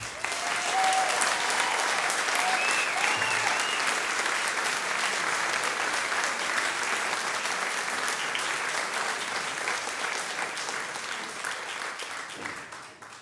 Applause 3
applaud applauding applause audience auditorium cheer cheering clap sound effect free sound royalty free Sound Effects